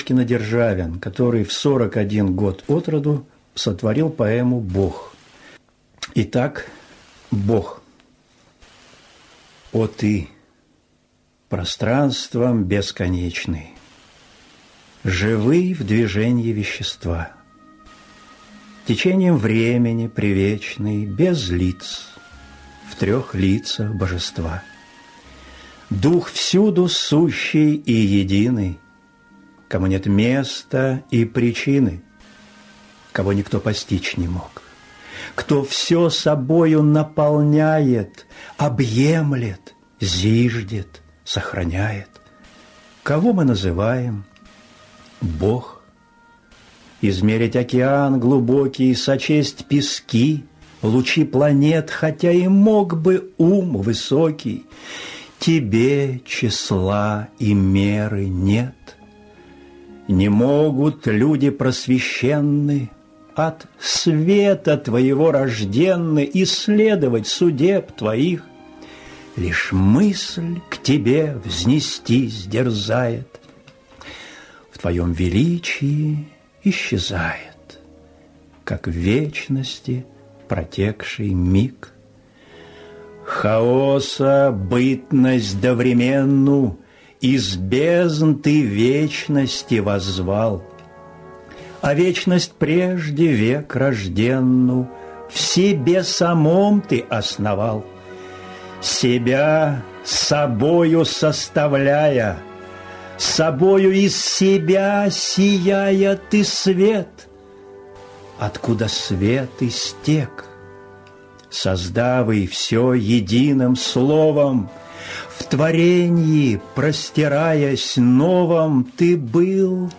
Прослушивание аудиозаписи оды «Бог» с сайта «Старое радио». Исп. Н. Бурляев.